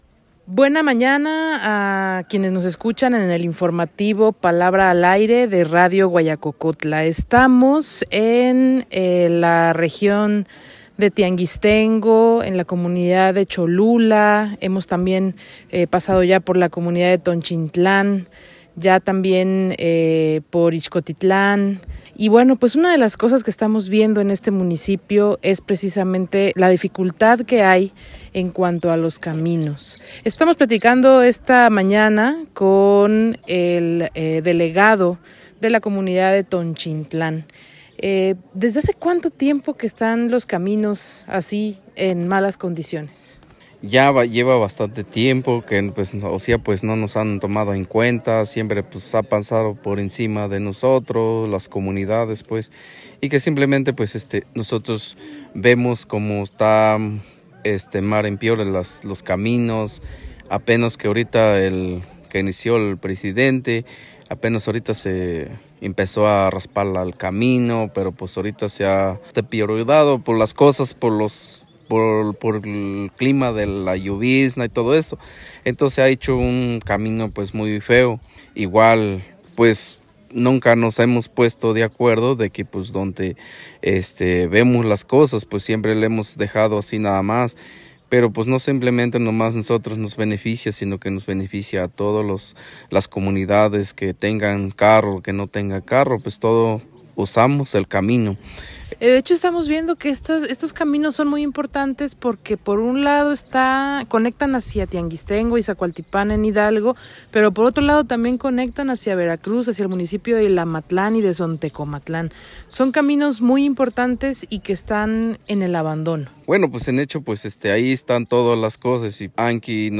Los caminos de la sierra están en mal estado y con la lluvia comienzan a descomponerse. Desde la comunidad de Cholula en el municipio de Tianguistengo, tenemos la palabra de la autoridad de la comunidad de Tonchintlán.